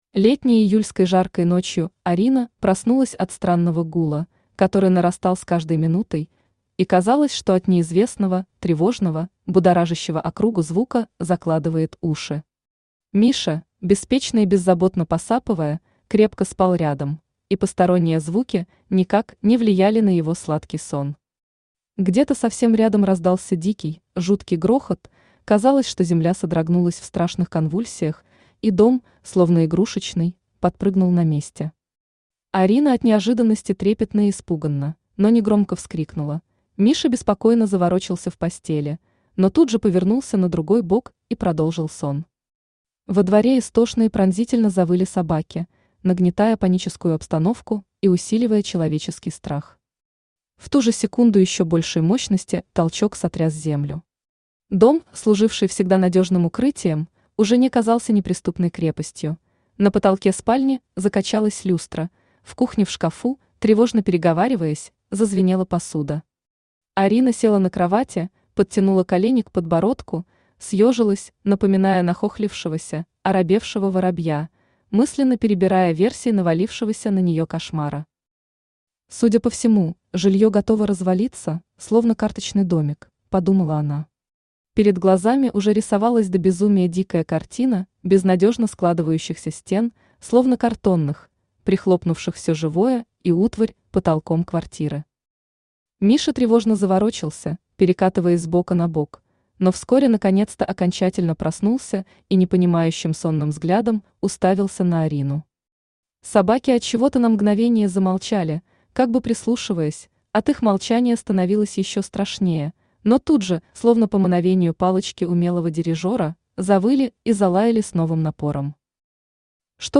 Аудиокнига Горше полыни мед | Библиотека аудиокниг
Aудиокнига Горше полыни мед Автор Марина Капранова Читает аудиокнигу Авточтец ЛитРес.